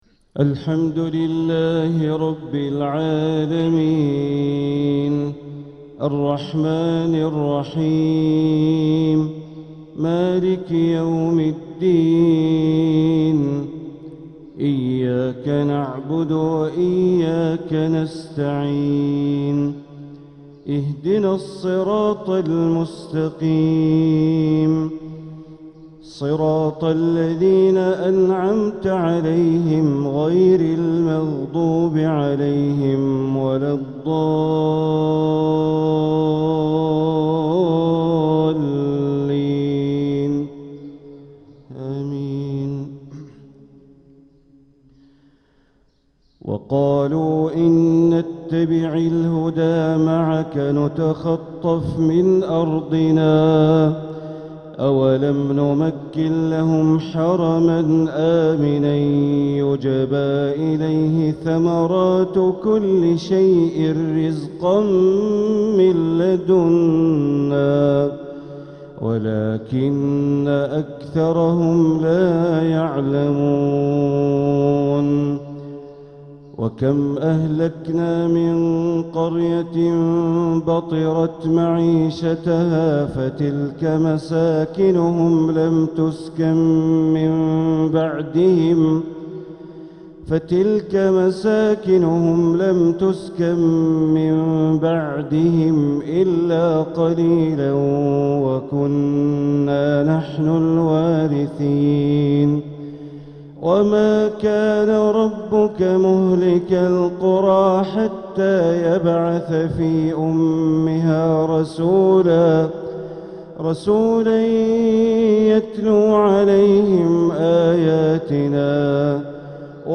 تلاوة من سورة القصص ٥٧-٧٥ | فجر السبت ٥ ربيع الآخر ١٤٤٧ > 1447هـ > الفروض - تلاوات بندر بليلة